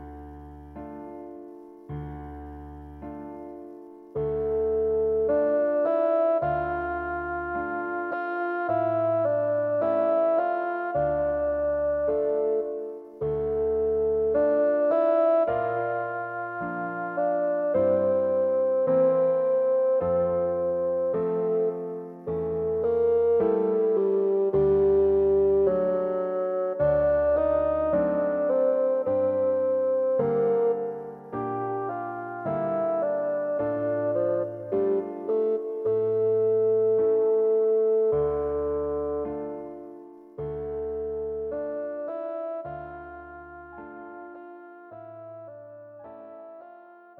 A most beautiful melody.
Arranged for solo Bassoon and piano.
Bassoon Solo and Piano A most beautiful melody.